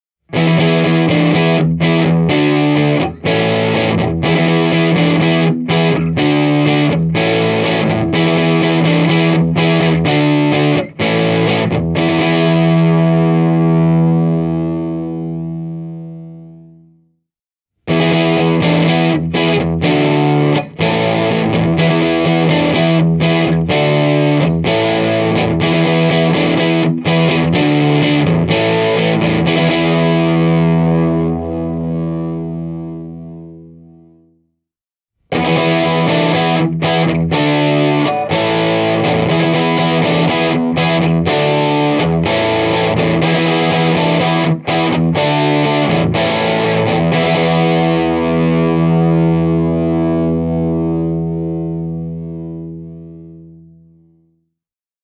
Särön luonne on juuri sellainen kuin voi odottaa pieneltä, vintage-tyyliseltä ja Fender-pohjaiselta kombolta. Purevuutta ja kompressiota löytyy sopivasti ja keskialueen sointi pysyy suhteellisen avoimena.
Tuplahumbucker-Hamerilla (Low-tuloon kytkettynä) sain tällaiset soundit aikaiseksi:
bluetone-princeton-reverb-e28093-hamer-full-gain.mp3